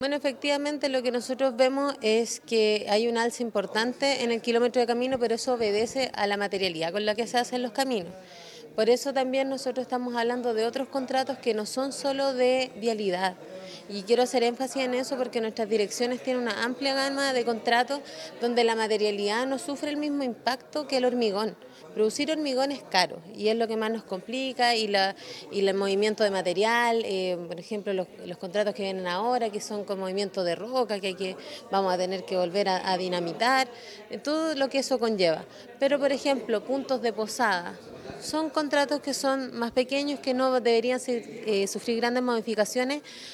Paloma Jara / Seremi Obras Públicas Aysén